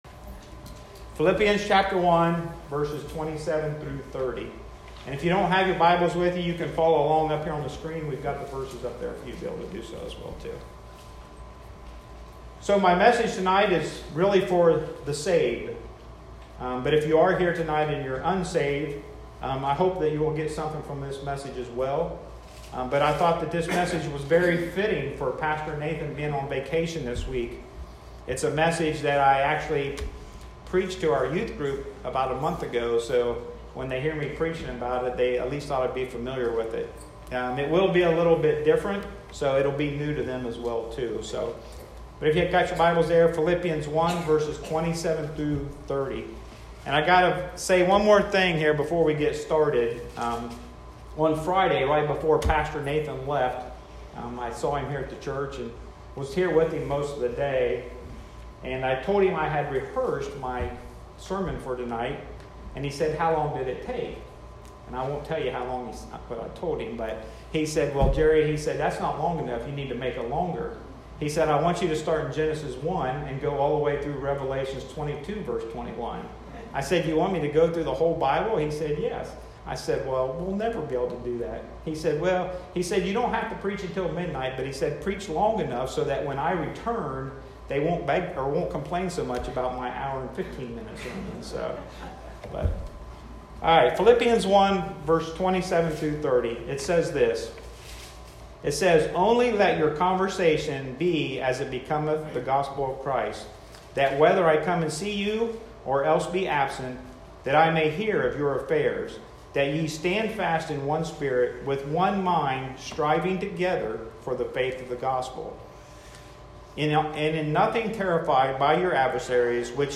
Sunday evening, May 23, 2021.